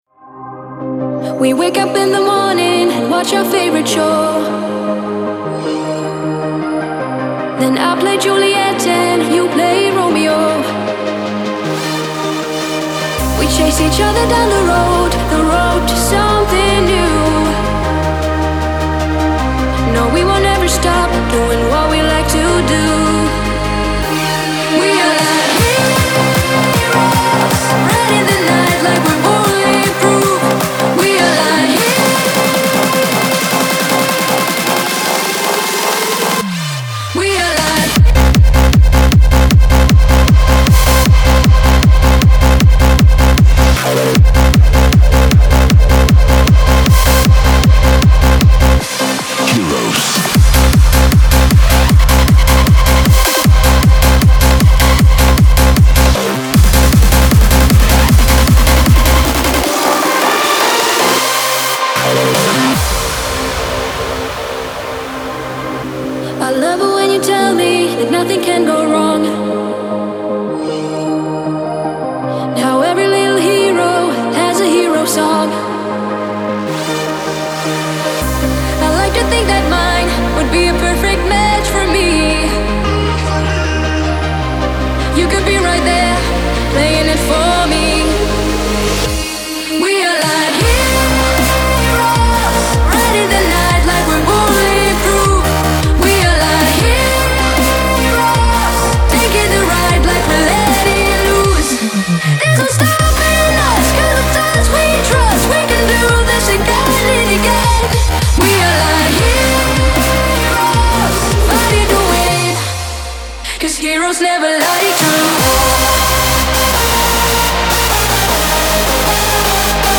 • Жанр: Pop, Electronic, Dance